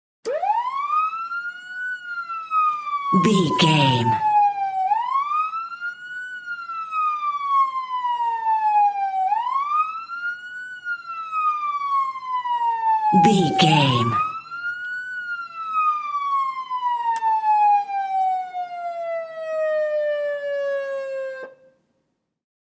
Ambulance Int Large Siren
Sound Effects
urban
chaotic
emergency